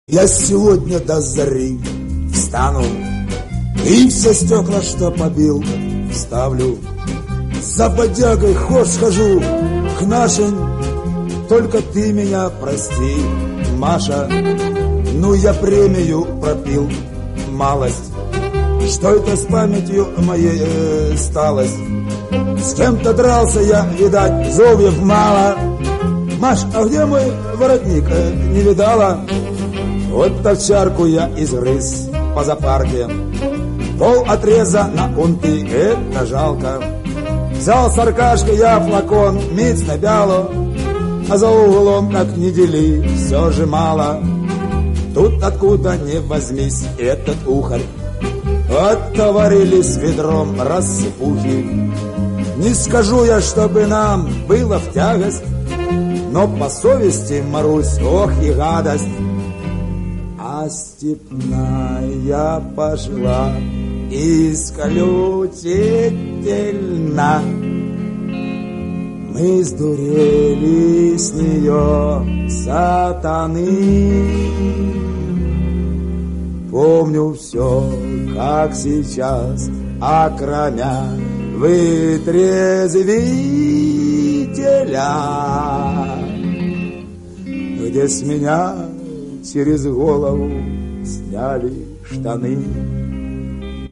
гитара
веселые